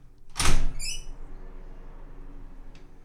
household
Window Aluminum Open Squeek